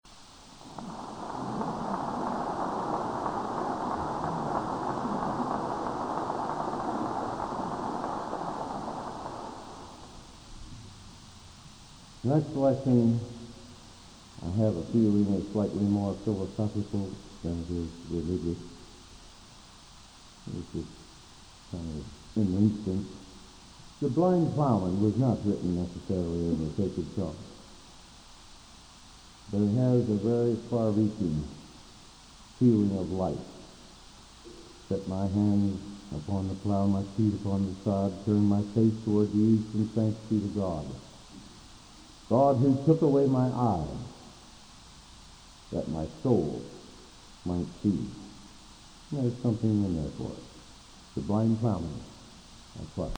Collection: Broadway Methodist, 1982